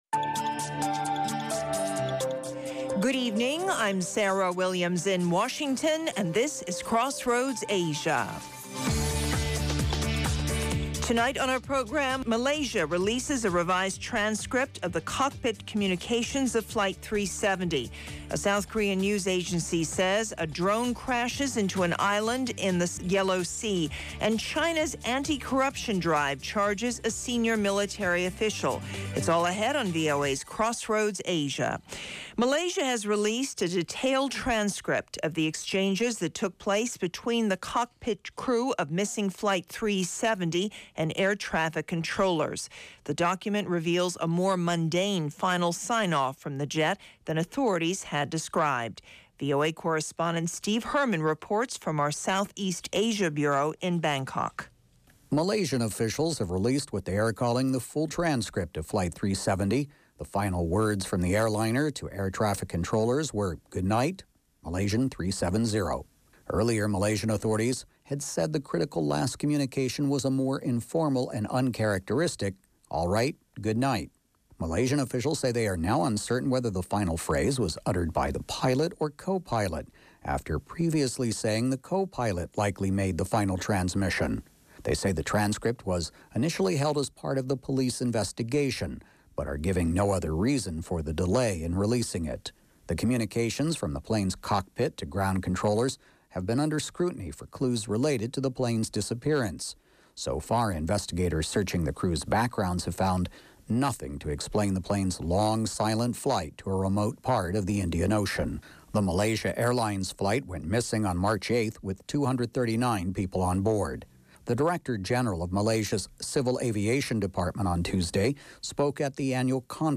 Crossroads Asia offers unique stories and perspectives -- with in-depth interviews, and analysis.